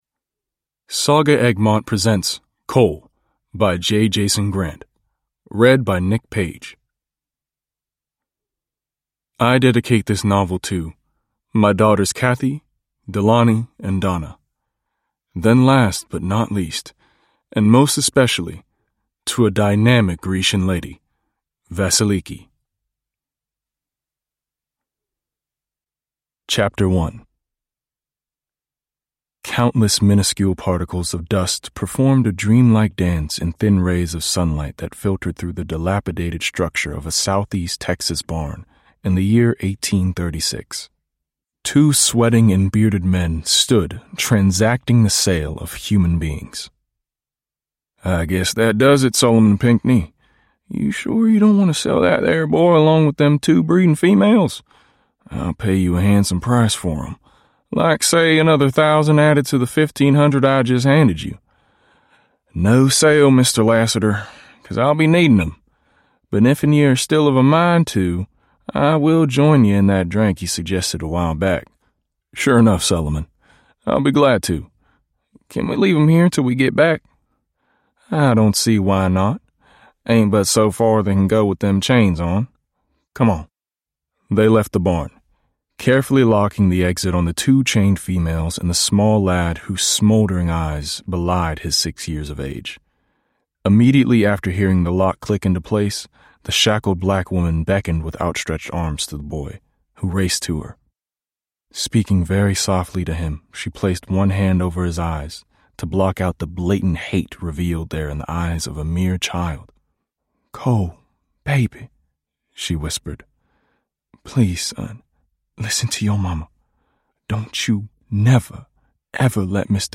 Coal: The action-packed western that inspired 'Django Unchained' – Ljudbok